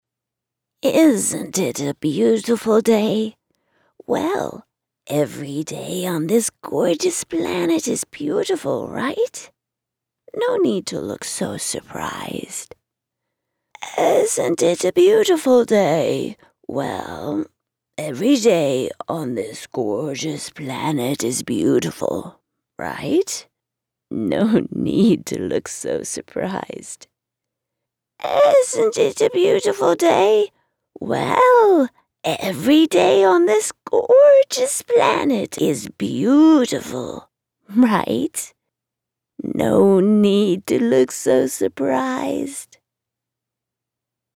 Female
English (North American)
Yng Adult (18-29), Adult (30-50)
Character / Cartoon
Old Character Voice 3 Styles